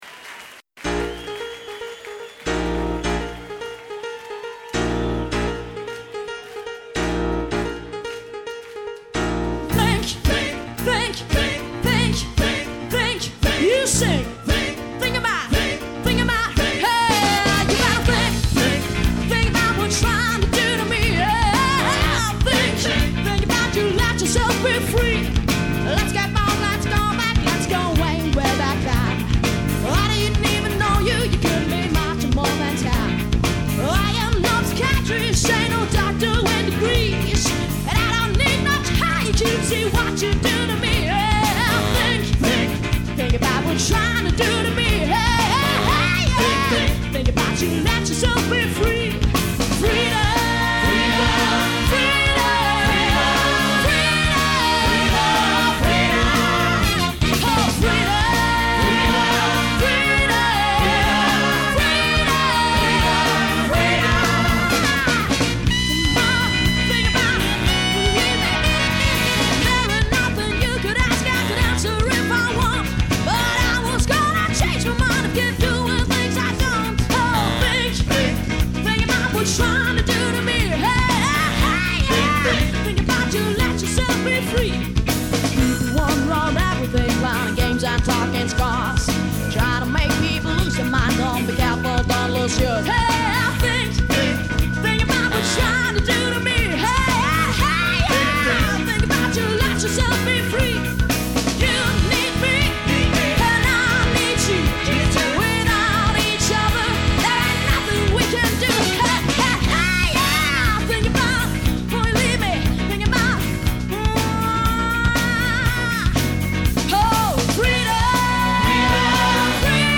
Eine Live-Aufnahme